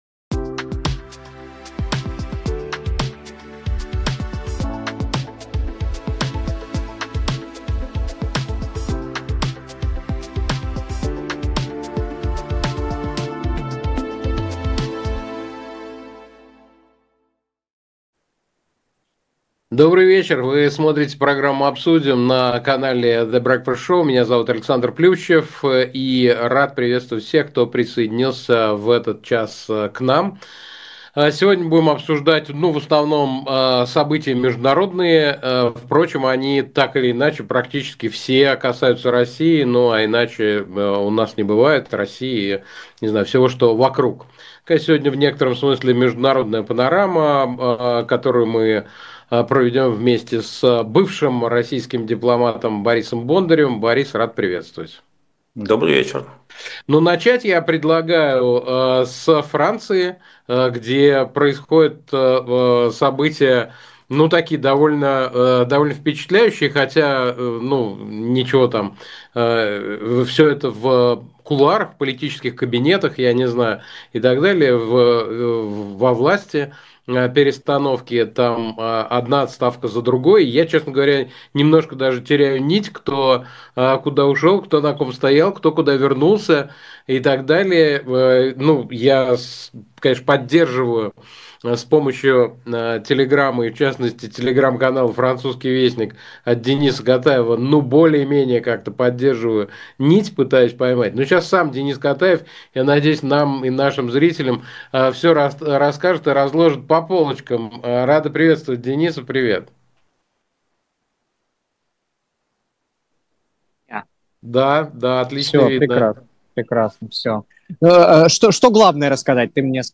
Эфир ведёт Александр Плющев